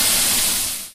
sounds_lava_cool.1.ogg